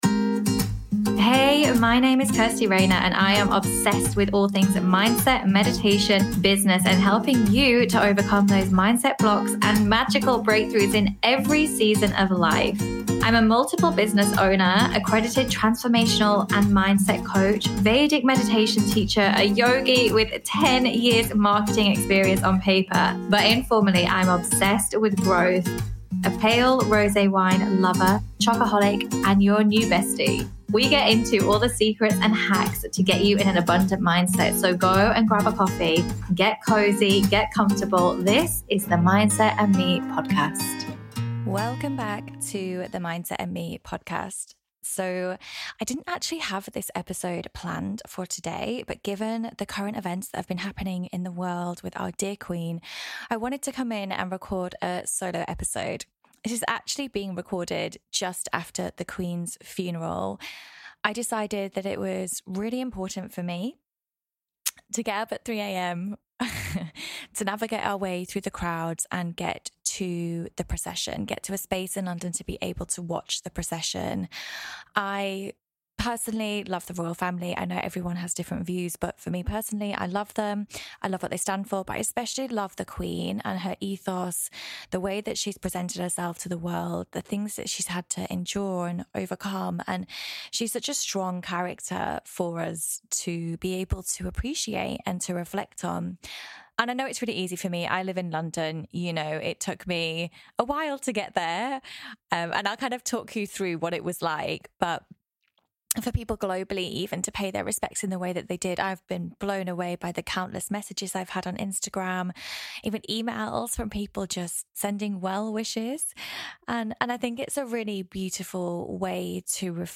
Solo Ep | Reflecting on the life of Queen Elizabeth || and how change can affect us all
Mini Meditation at the end